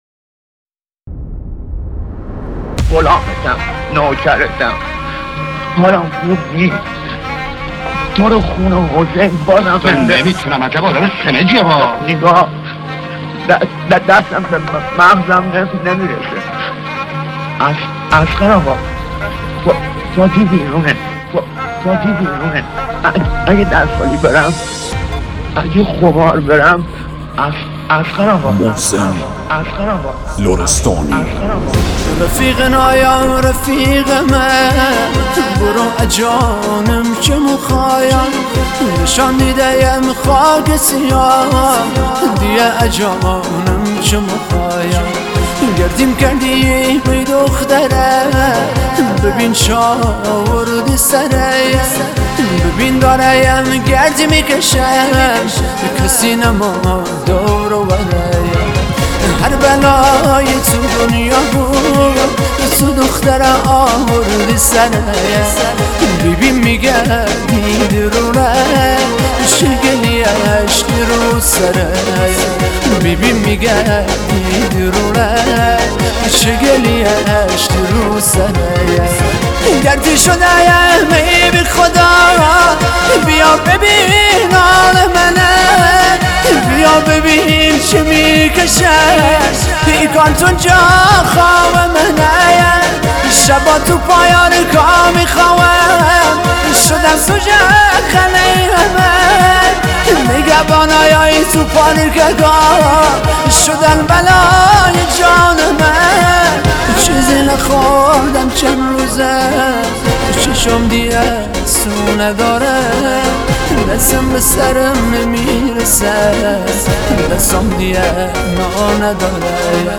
پاپ عاشقانه غمگین